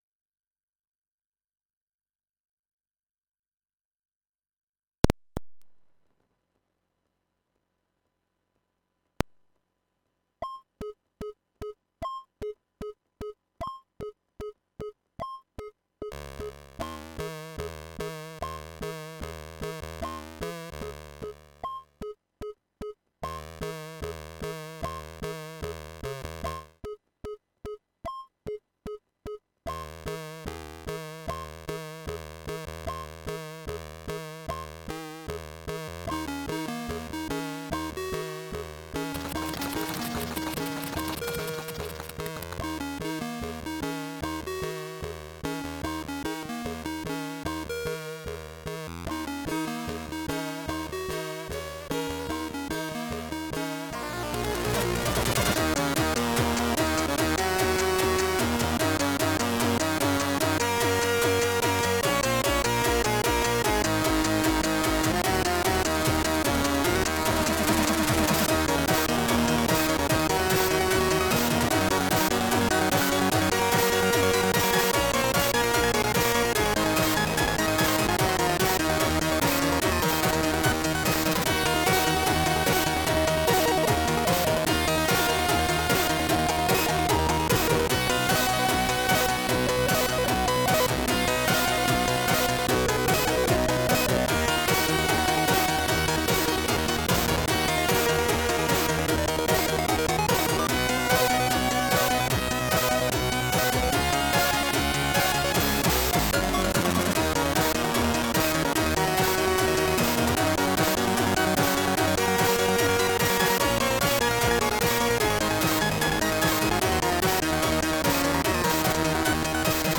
some tunes just fine, with the only issue being in my test rig: lag from piping the register updates over a 115200 UART for playback.